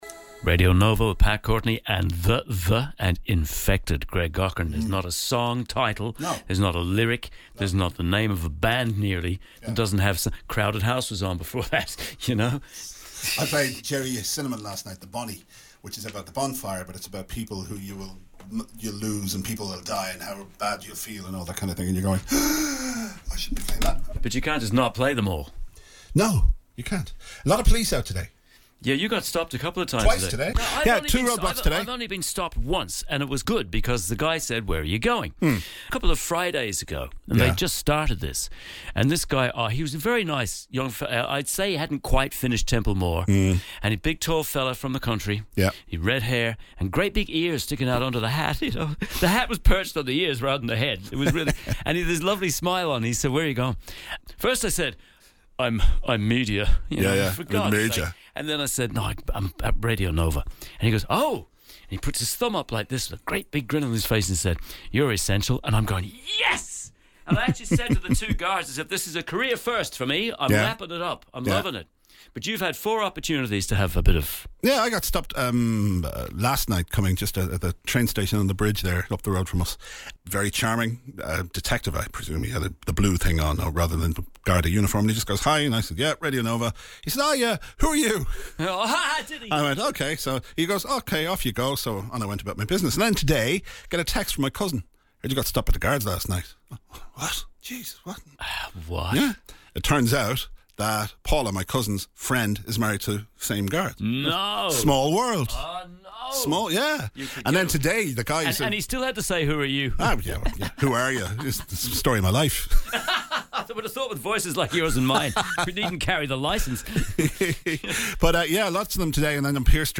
They squabble, fight, laugh and pontificate from a studio somewhere in Dublin.